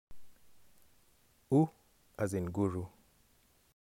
u as in Nguru